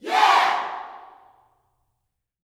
YEAH  10.wav